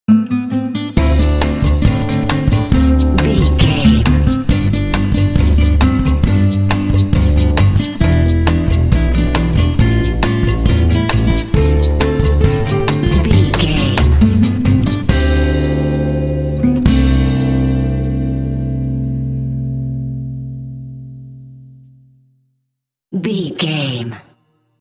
Ionian/Major
light
playful
uplifting
calm
cheerful/happy
electric guitar
bass guitar
percussion
acoustic guitar